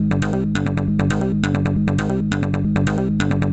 techno synth bass sequence 136 1.wav
techno_synth_bass_sequence_136_1_6lq.ogg